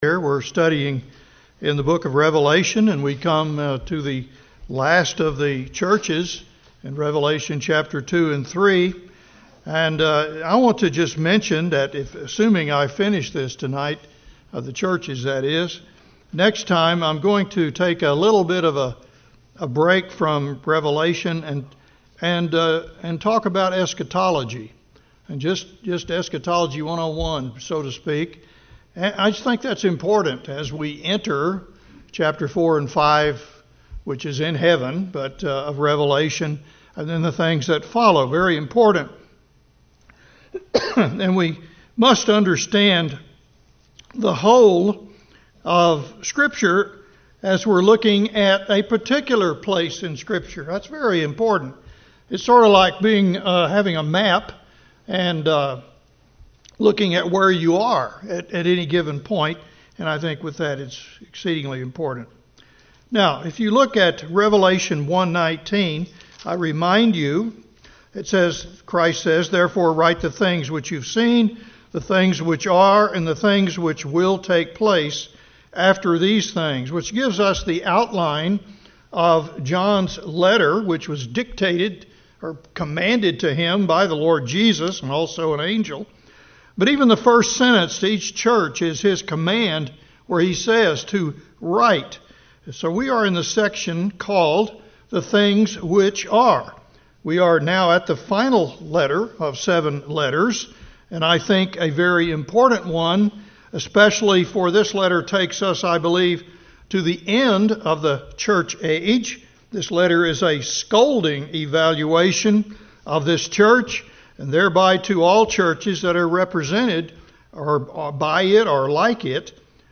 Grace Bible Chapel Non Denominational bible church verse-by-verse teaching